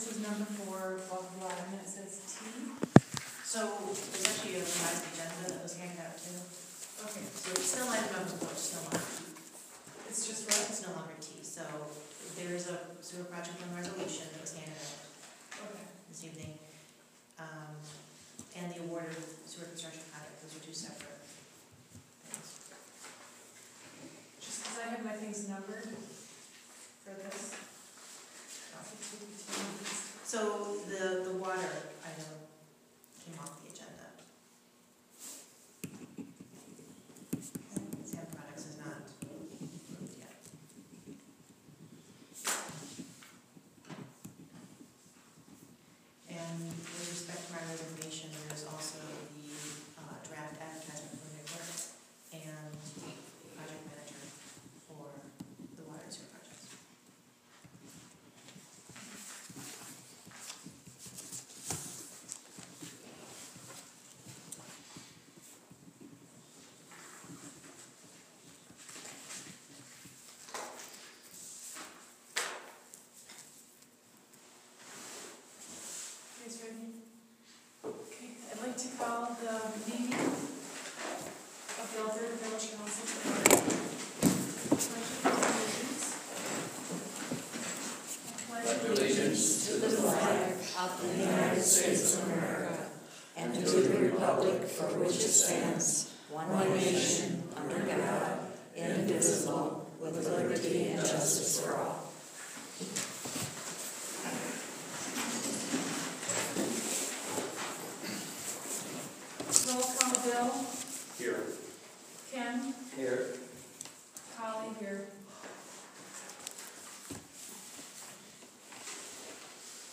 Herewith, audio from the May 18, 2017 Regular Council Meeting.